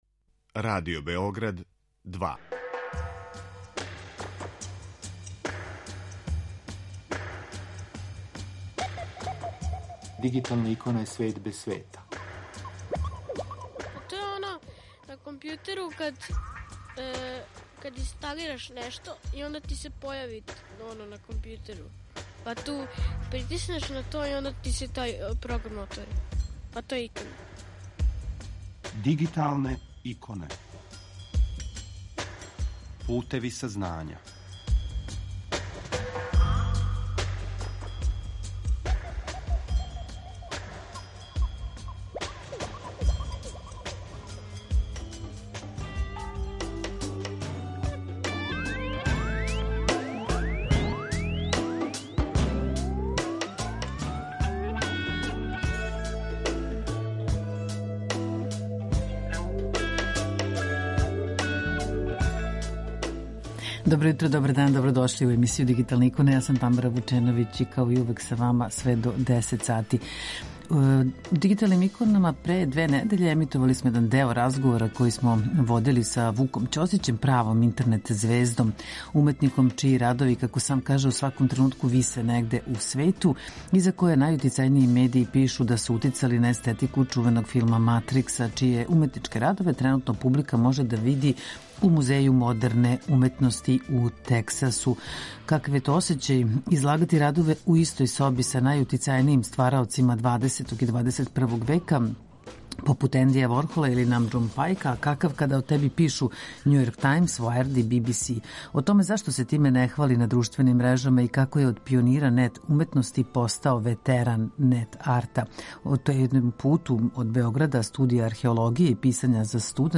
У данашњој емисији настављамо разговор са Вуком Ћосићем, интернет суперстаром, активистом и уметником чији радови у сваком тренутку ''висе'' негде у свету и за које најутицајнији медији пишу да су, између осталог, утицали на естетику чувеног филма Матрикс.